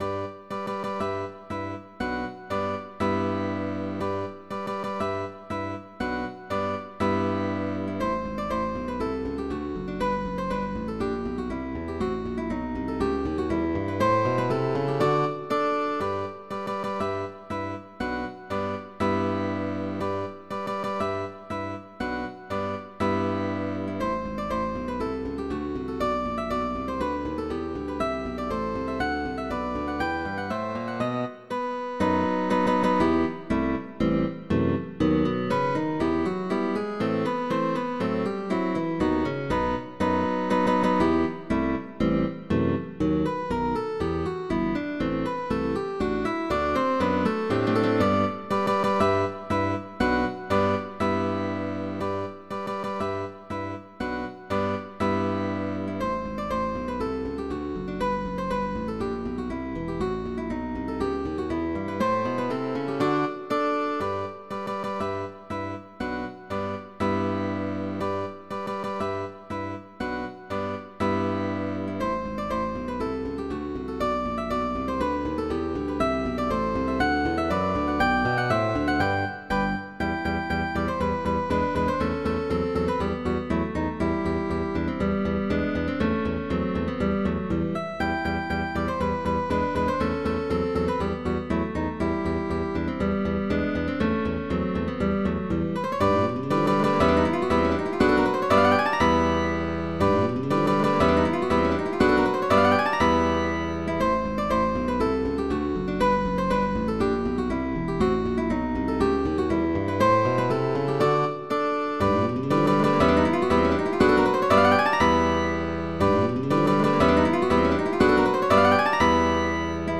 guitar quartet with bass
GUITAR QUARTET